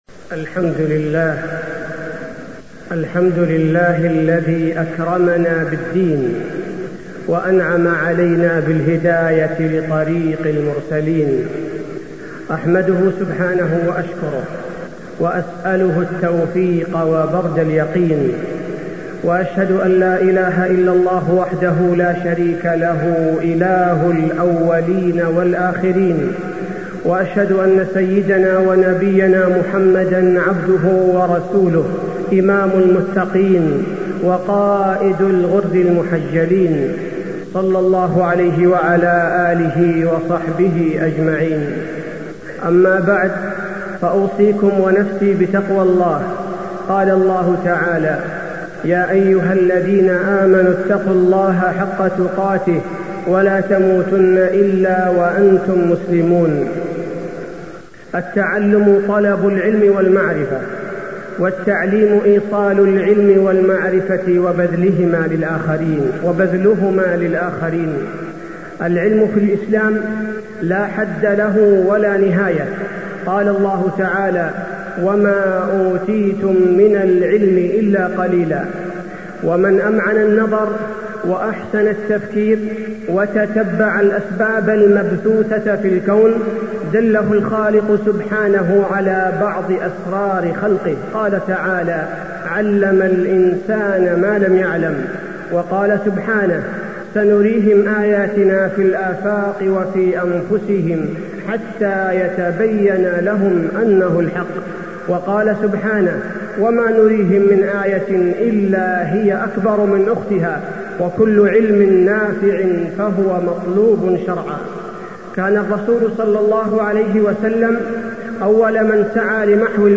تاريخ النشر ٣ شعبان ١٤٢٥ هـ المكان: المسجد النبوي الشيخ: فضيلة الشيخ عبدالباري الثبيتي فضيلة الشيخ عبدالباري الثبيتي المعلم والتعليم